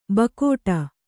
♪ bakōṭa